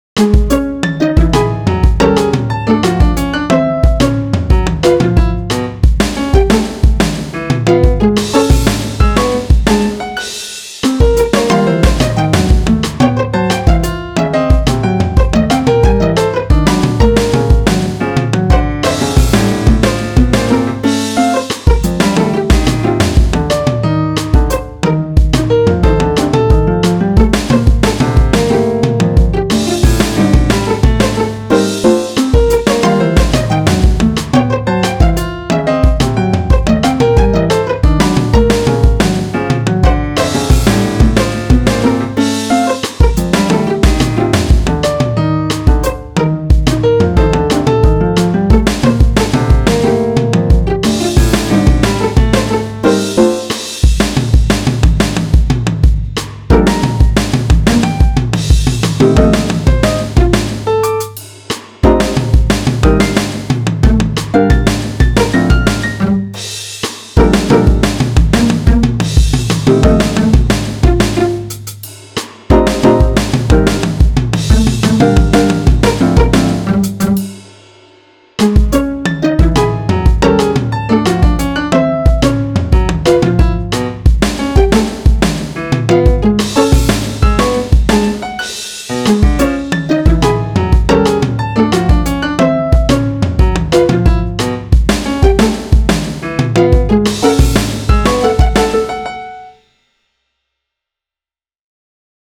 light-hearted